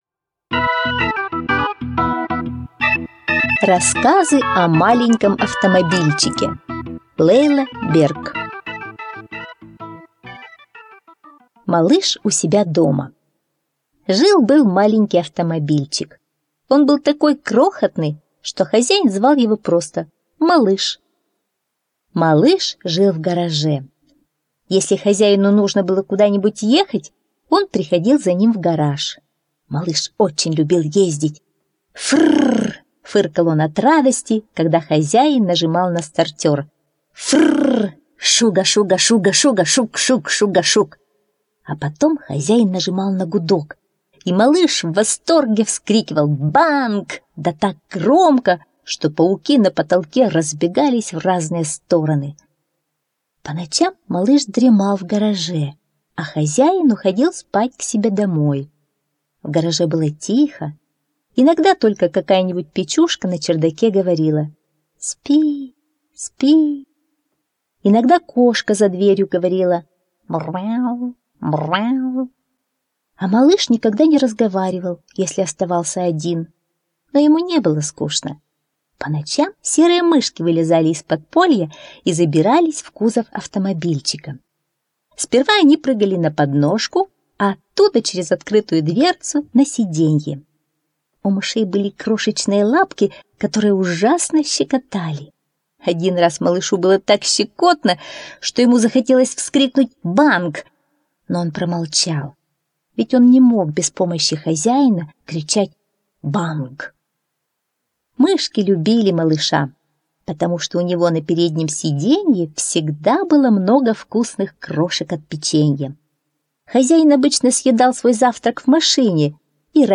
Рассказы о маленьком автомобильчике - аудиосказка Берг